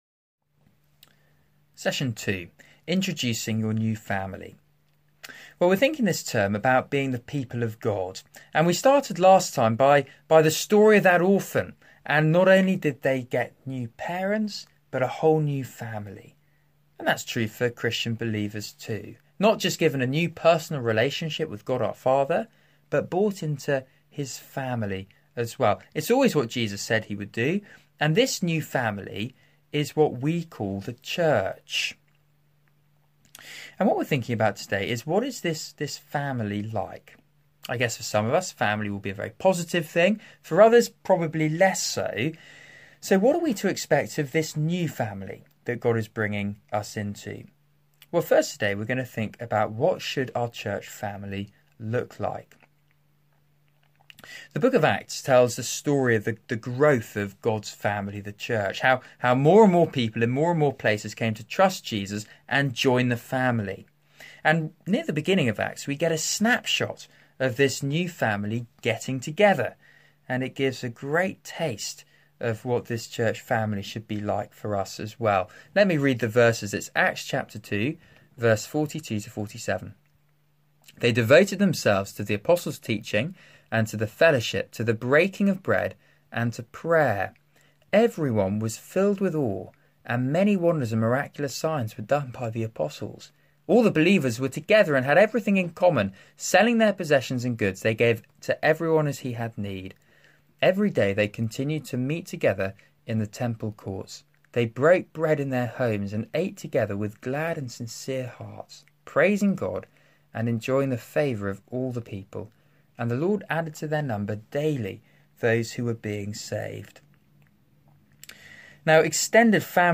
Series: Discipleship Course- Being God's people today Theme: Introducing your new family Talk